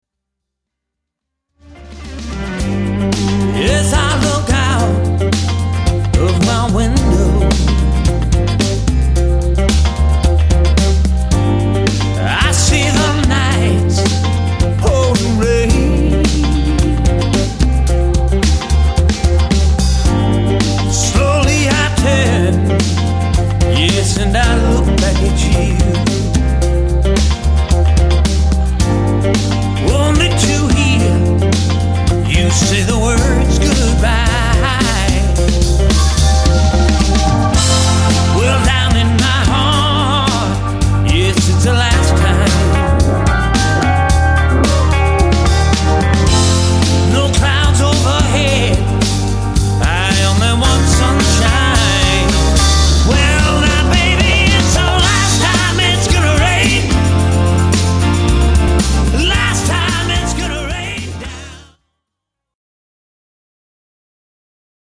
lead vocals and all guitars
drums on all tracks
bass on all tracks
keys, B-3 on all tracks
keys, piano and Wurlizer
percussion
all strings
backing vocal